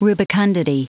Prononciation du mot rubicundity en anglais (fichier audio)
Prononciation du mot : rubicundity